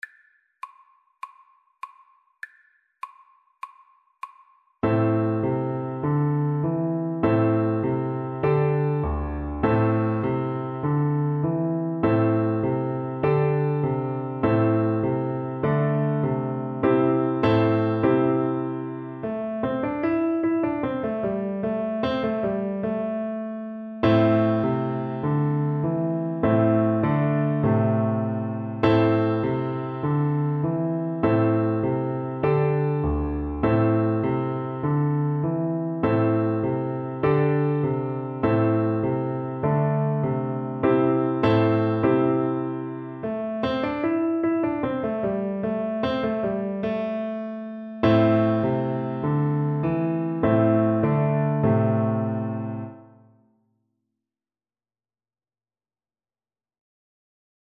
Play (or use space bar on your keyboard) Pause Music Playalong - Piano Accompaniment Playalong Band Accompaniment not yet available transpose reset tempo print settings full screen
12/8 (View more 12/8 Music)
A minor (Sounding Pitch) (View more A minor Music for Flute )
Moderato .=100
Traditional (View more Traditional Flute Music)